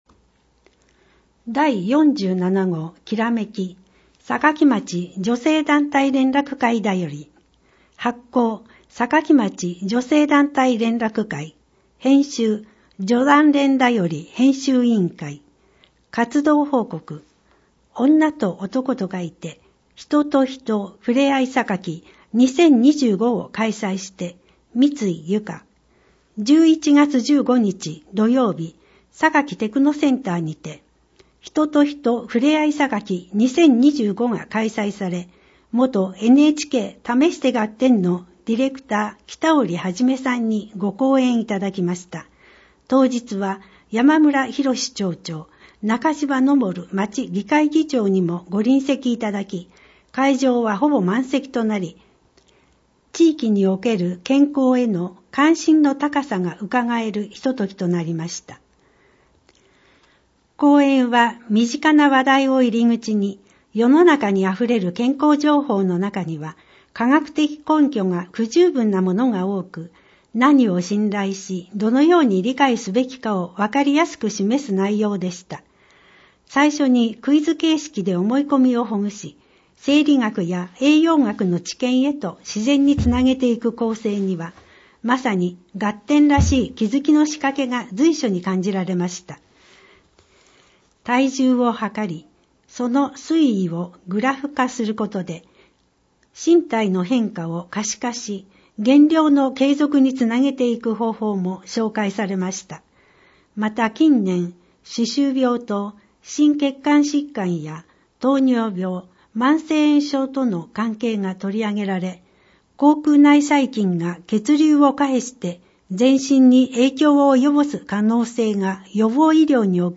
また、音訳ボランティアサークルおとわの会のみなさんによる広報の音訳版のダウンロードもご利用ください。